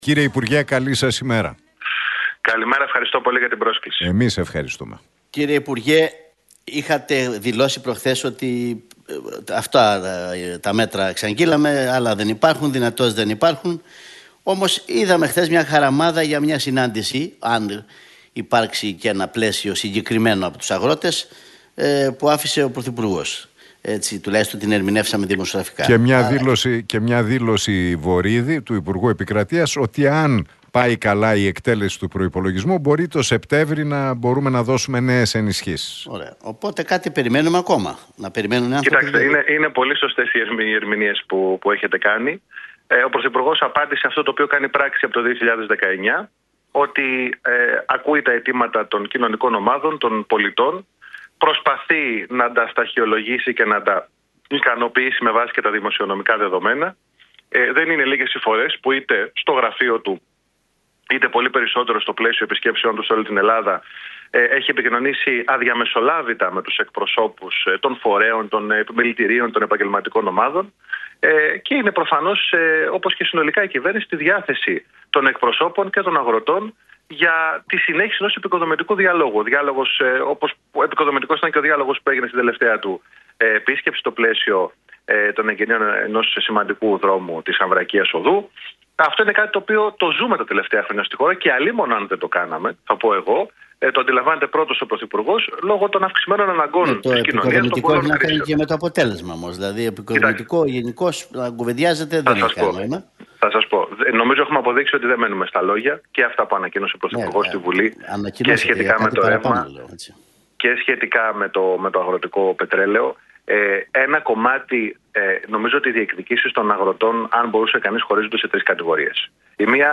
Για τις αγροτικές κινητοποιήσεις μίλησε μεταξύ άλλων ο Κυβερνητικός Εκπρόσωπος, Παύλος Μαρινάκης στον Realfm 97,8 και την εκπομπή του Νίκου Χατζηνικολάου με